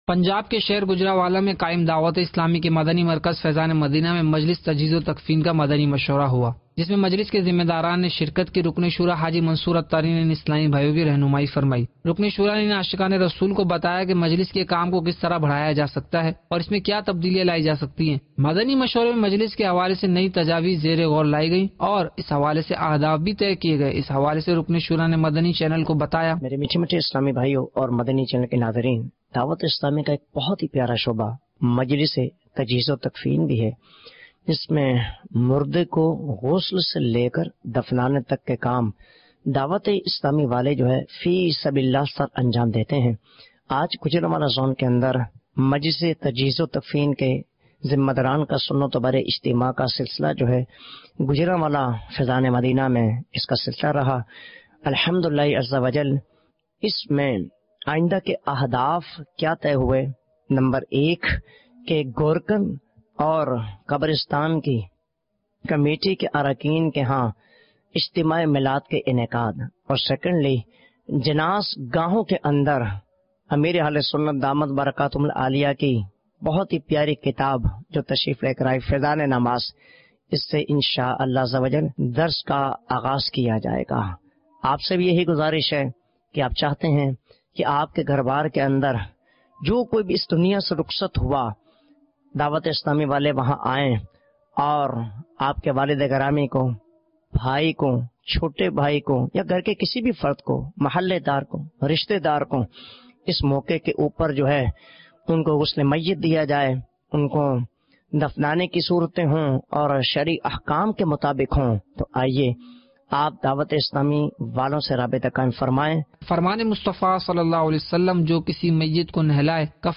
News clip Urdu - 03 December 2019 - Gujranwala Main Majlis Tajheez o Takfeen Kay Zimadaran Ka Madani Mashwara Jan 11, 2020 MP3 MP4 MP3 Share گو جرانوالہ میں مجلس تجہیز و تکفین کے زون ذمہ داران کا مدنی مشورہ